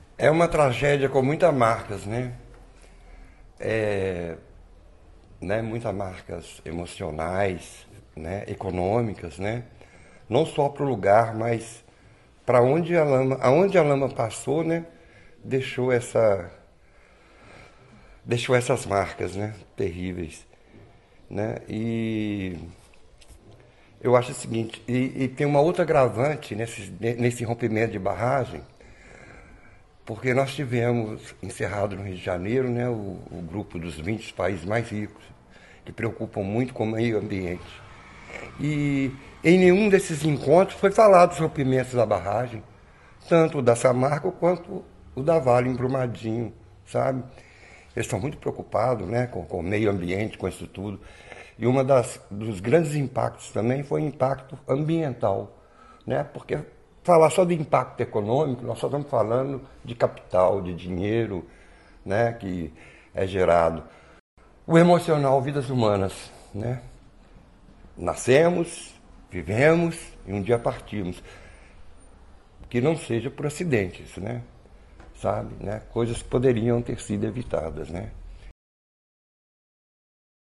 Foi notável a tristeza e a revolta na fala do morador.
A dor de conviver com esse momento tão traumático é palpável nas palavras dele.